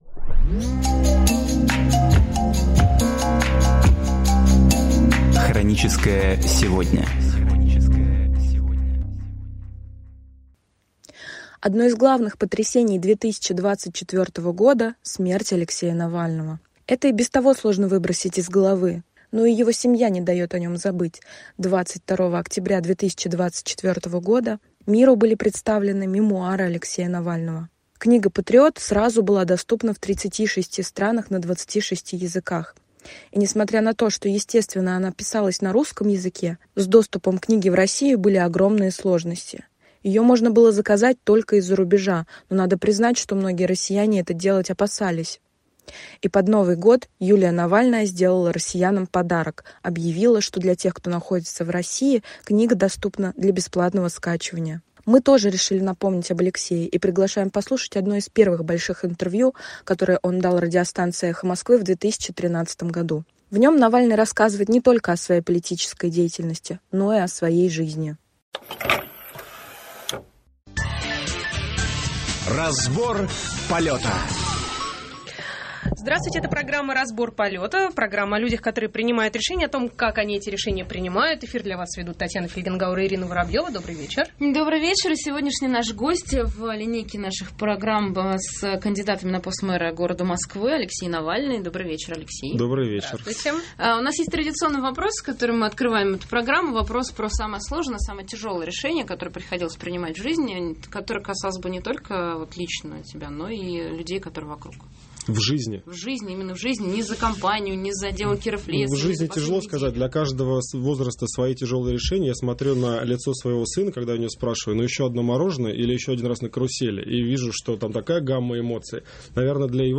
Мы тоже решили напомнить об Алексее и приглашаем послушать одно из первых больших интервью, который он дал радиостанции «Эхо Москвы» в 2013 году. В нем Навальный рассказывает не только о своей политической деятельности, но и своей жизни.